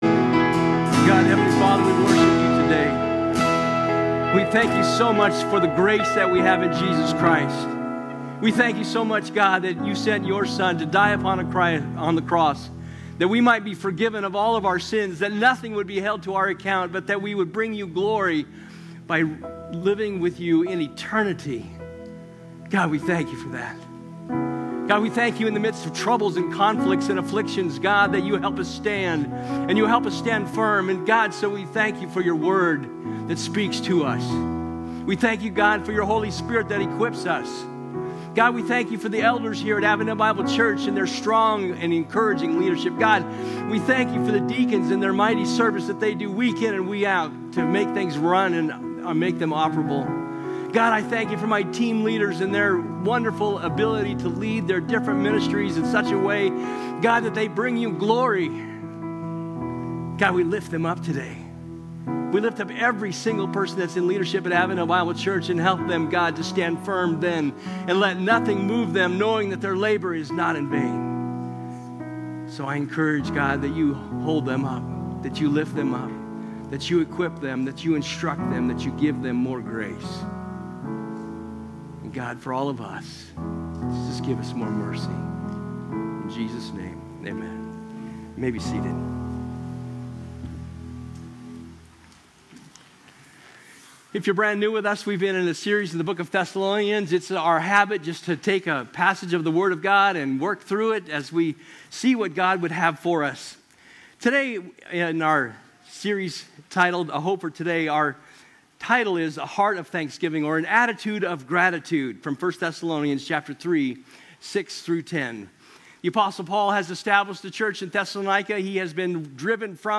Sermon Archive | Avondale Bible Church